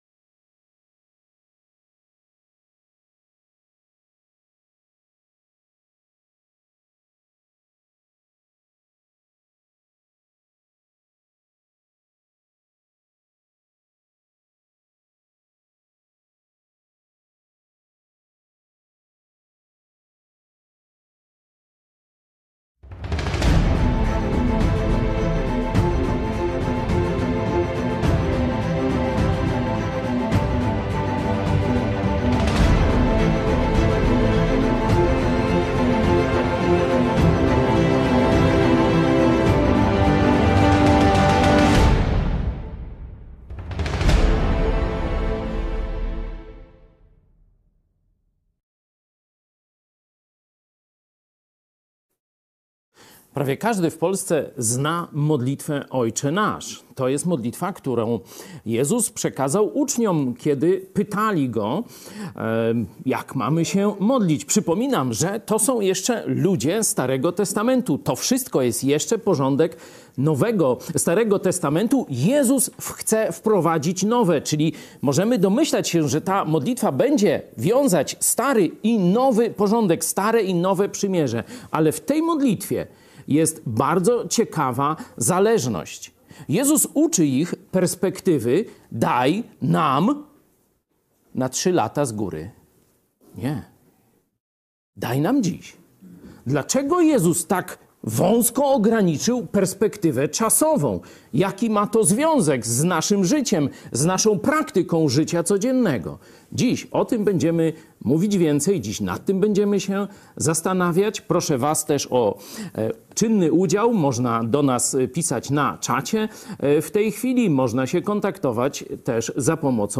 Nauczanie